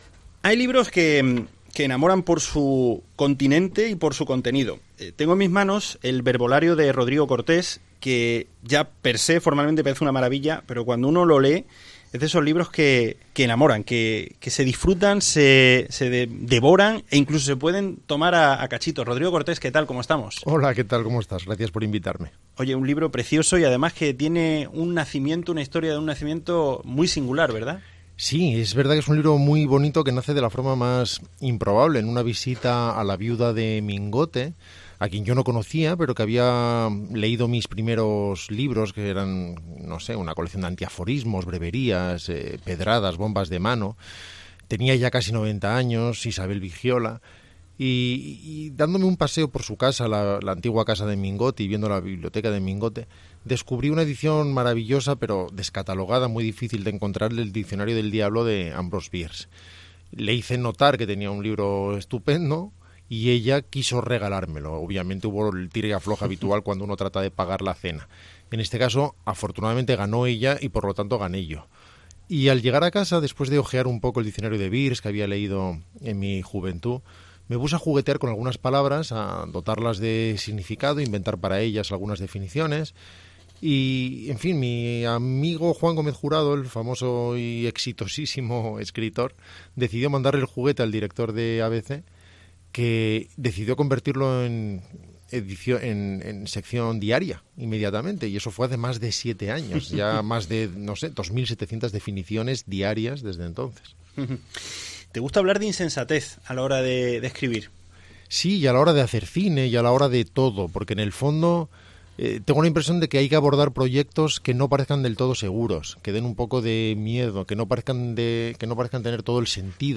Entrevista a Rodrigo Cortés: “Es más importante tener gracia que tener razón”